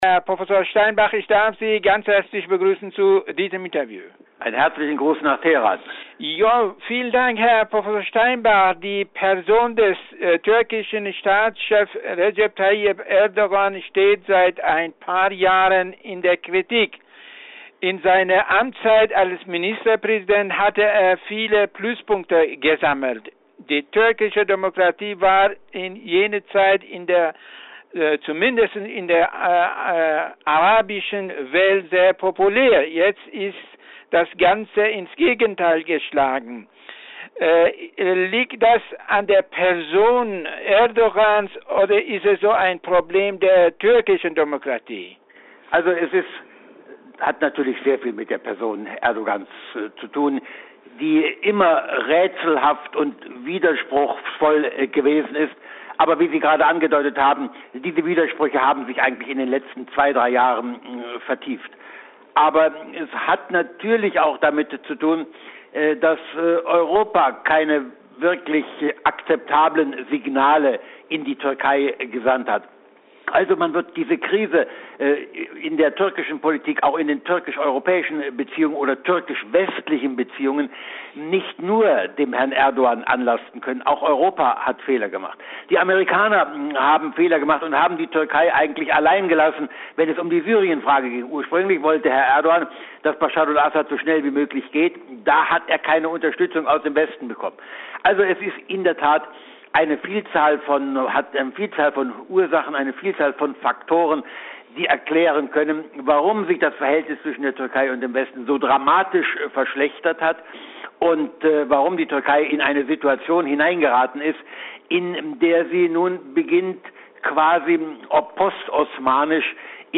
Interview mit Prof. Udo Steinbach